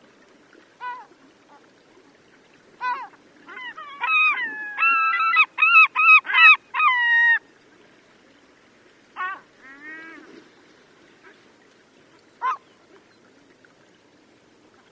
• vega gulls yelling.wav
vega_gulls-2_1x6.wav